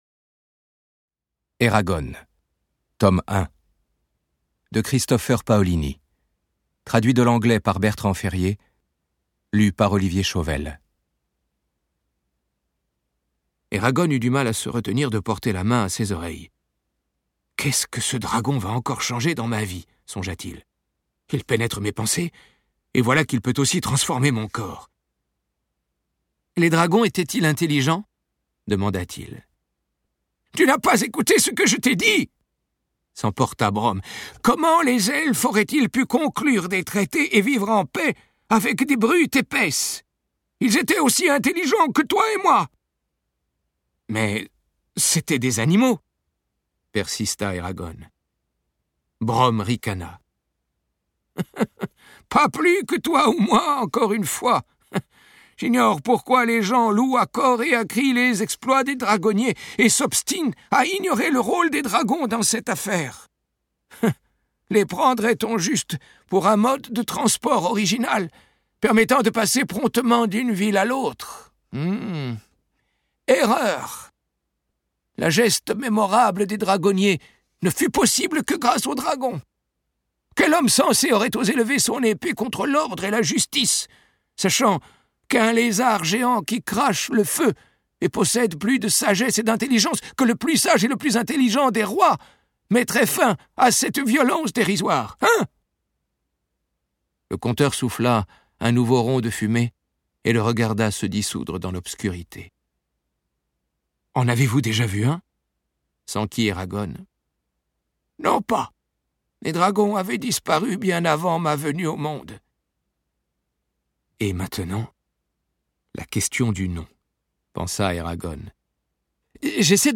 Il module sa voix pour leur donner vie, pour ajouter encore plus de rythme au récit, ajouter une tension, du suspens.